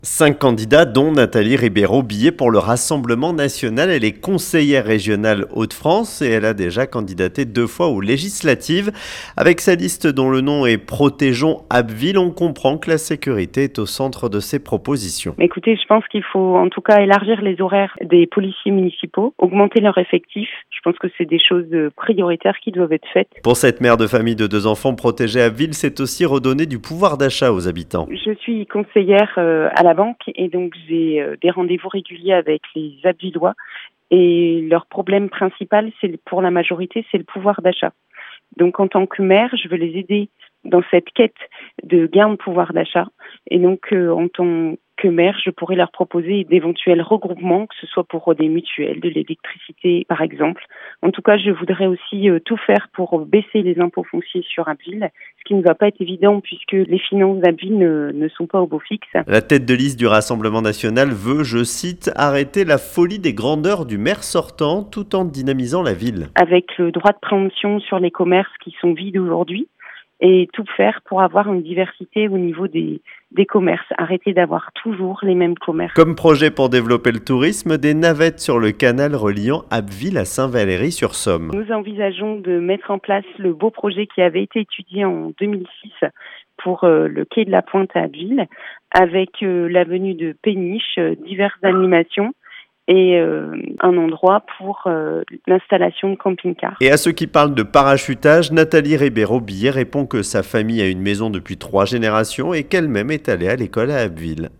ENTRETIEN : Nathalie Ribeiro-Bier (UDR - RN) veut "protéger et dynamiser" Abbeville